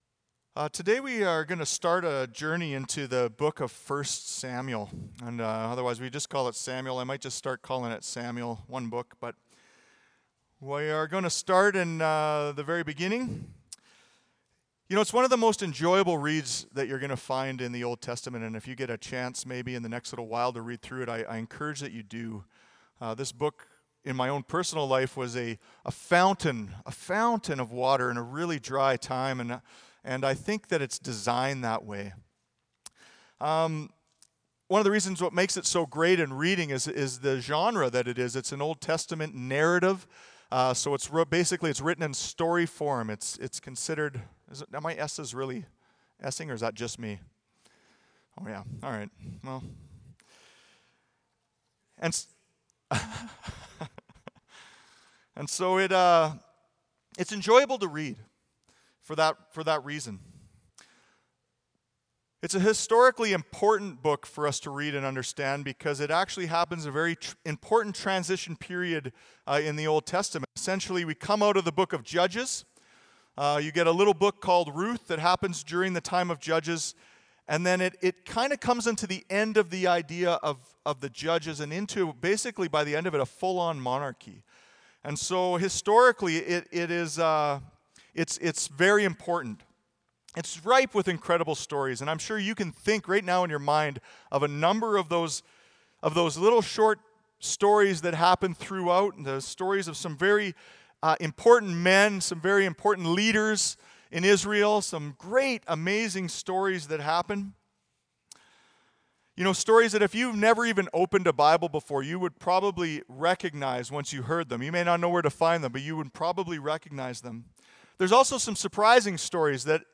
Audio Sermon Library A Leader Like No Other.